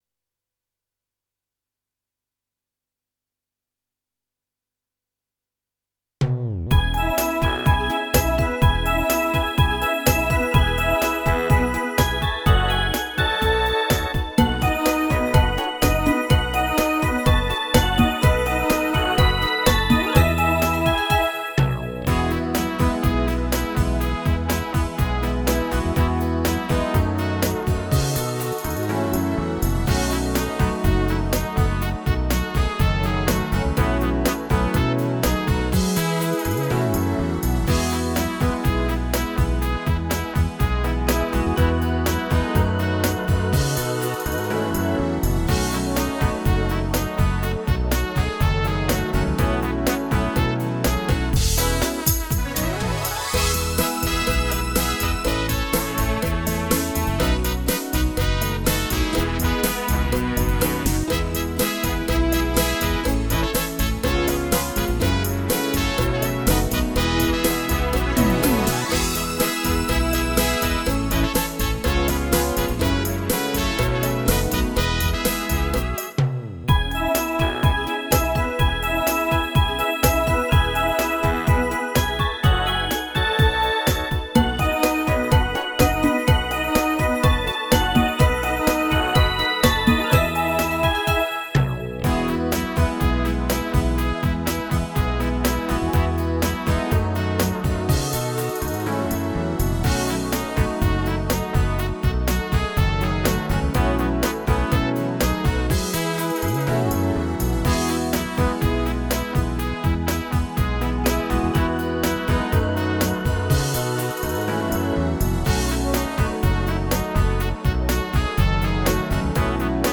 Рубрика: Поезія, Авторська пісня
Чудово, легко, оптимістично. 16 39 39